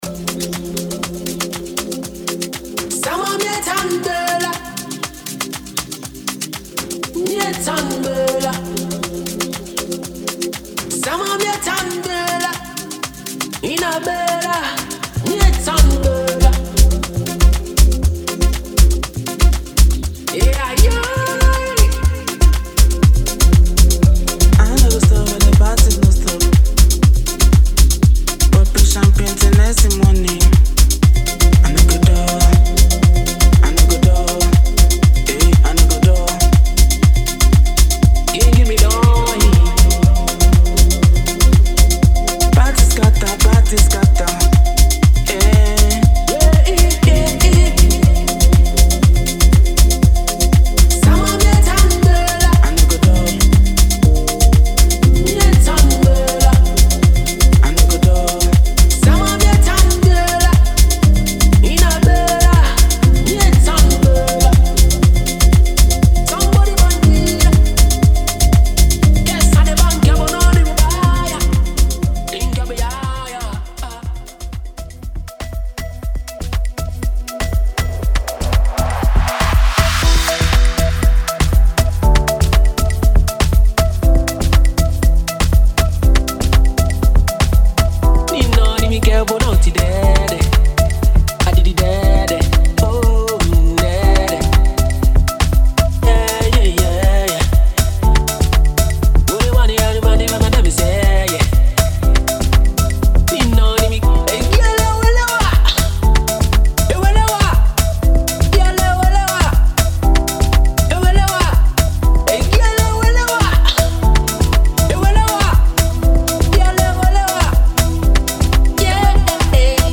Genre:Afro House
デモサウンドはコチラ↓